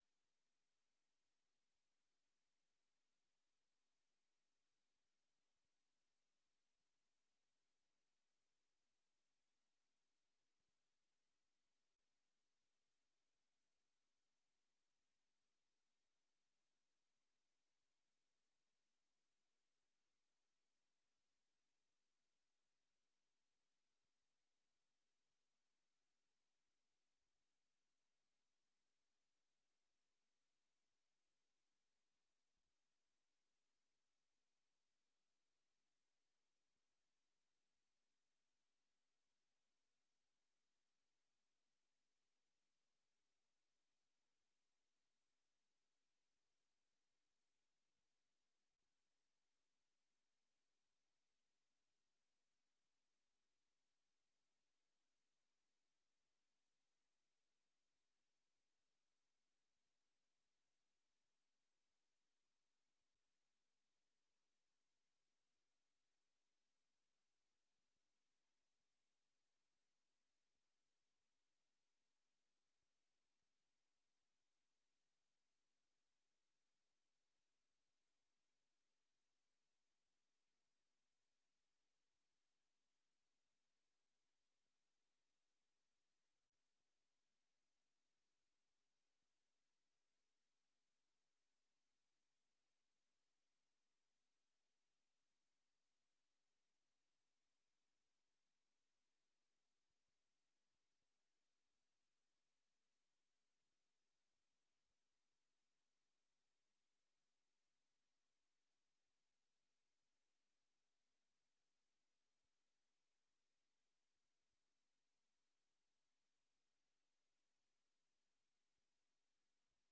Raadsvergadering 02 februari 2023 19:30:00, Gemeente Dronten
Download de volledige audio van deze vergadering